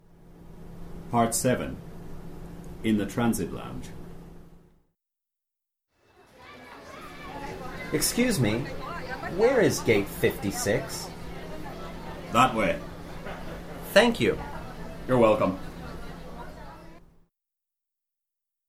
A business English dialog series (with questions and answers for beginning level learners)